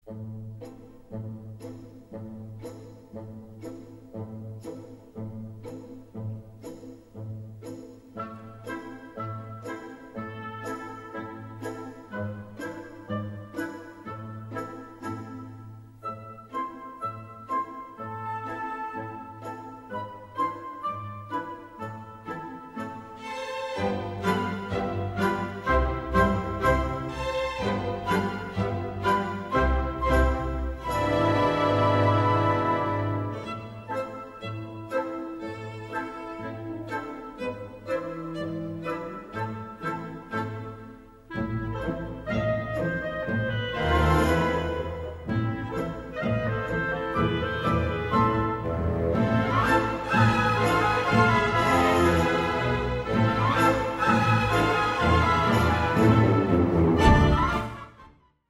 吉普賽樂曲